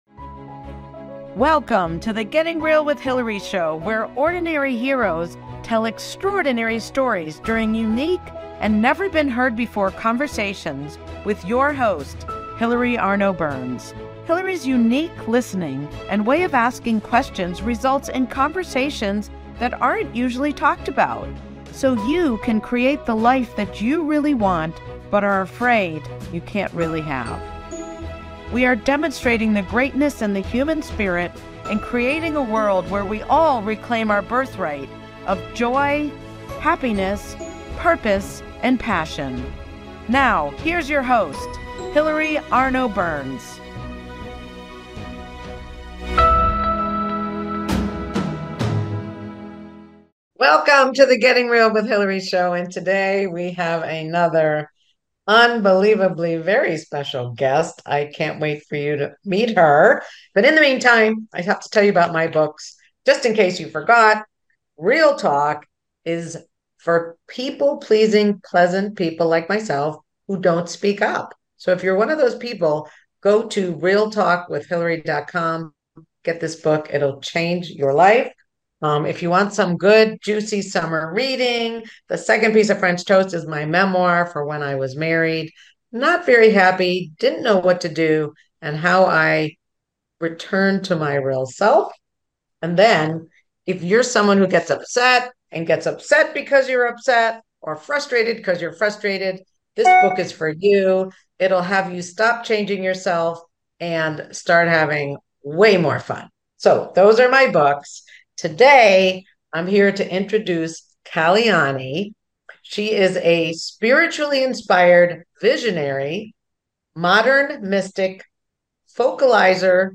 Talk Show Episode
Her Brazilian accent and inner and outer beauty held me captive. She describes other worldly events as if they happen every day.